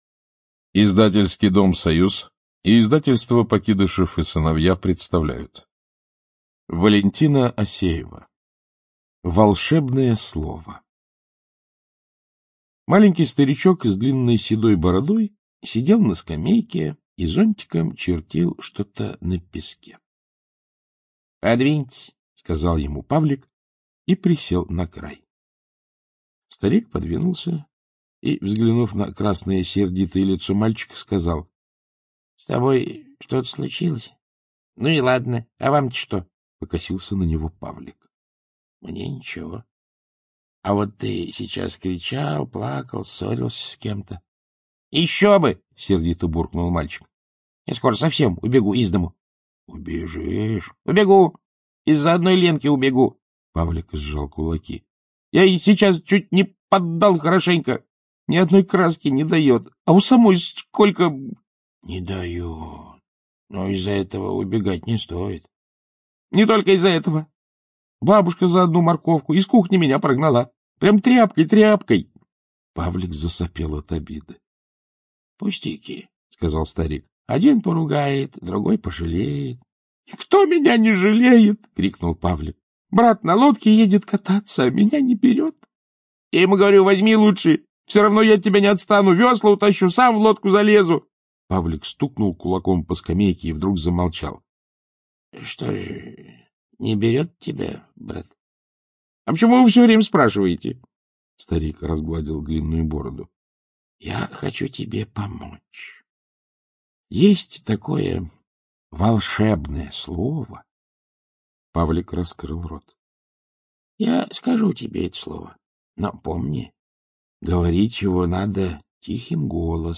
ЧитаетКлюквин А.